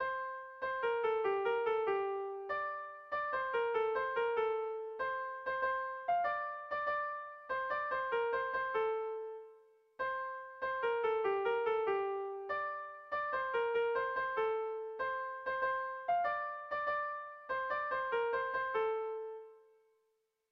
Gabonetakoa
Zortziko txikia (hg) / Lau puntuko txikia (ip)
ABAB